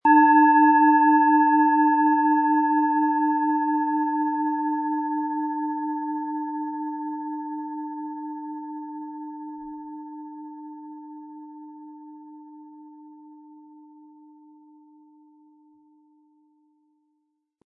Planetenton 1
Es ist eine nach uralter Tradition von Hand getriebene Planetenton-Klangschale Venus.
Um den Originalton der Schale anzuhören, gehen Sie bitte zu unserer Klangaufnahme unter dem Produktbild.
SchalenformBihar
GewichtCa. 181 gr
MaterialBronze